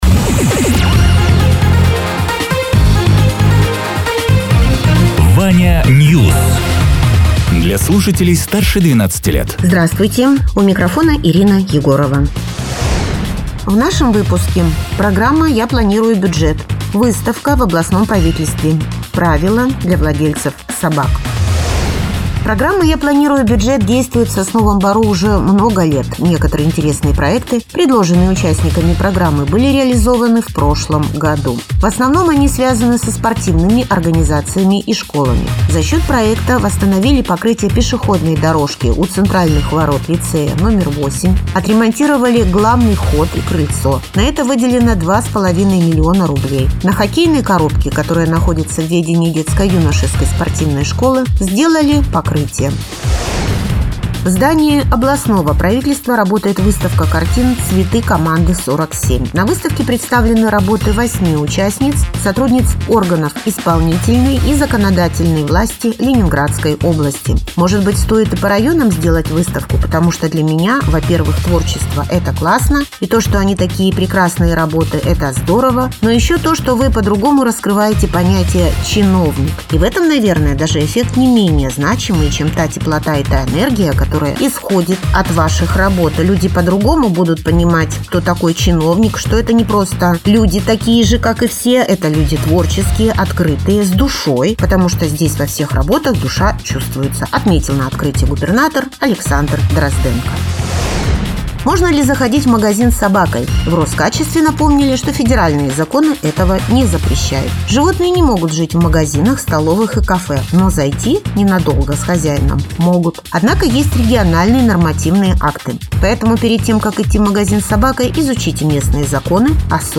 Радио ТЕРА 18.03.2026_12.00_Новости_Соснового_Бора